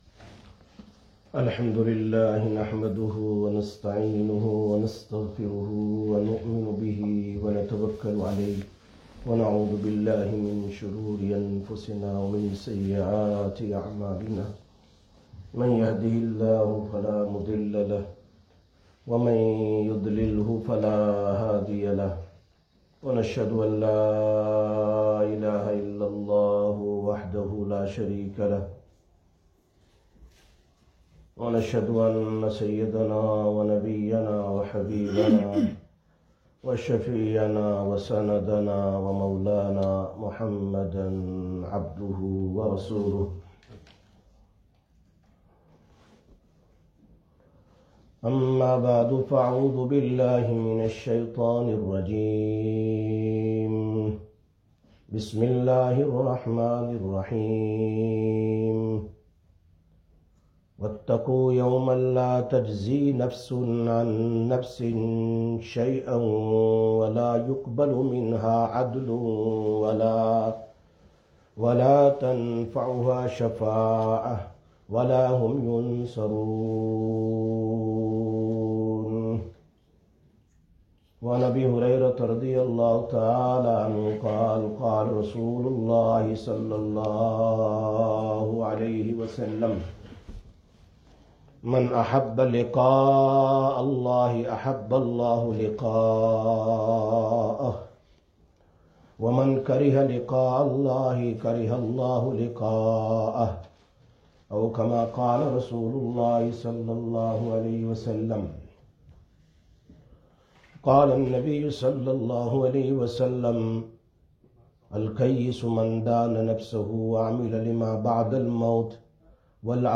28/05/2021 Jumma Bayan, Masjid Quba